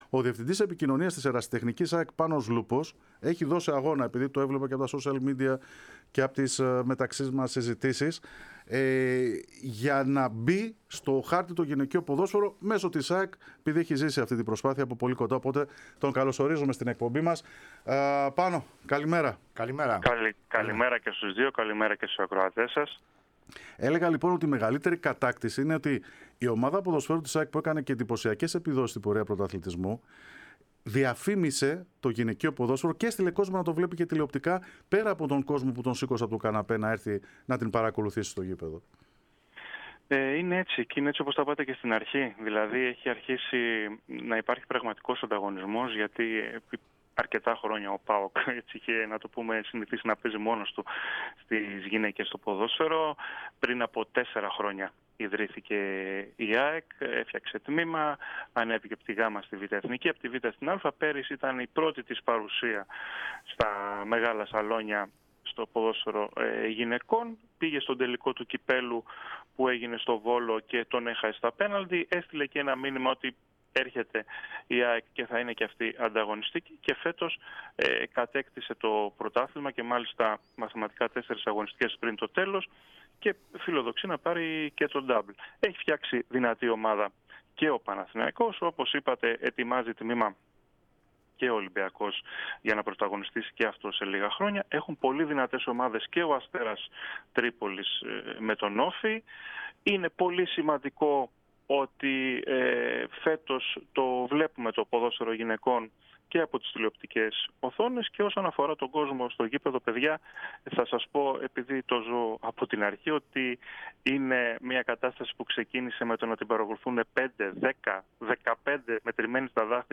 μίλησε στον αέρα της ΕΡΑ ΣΠΟΡ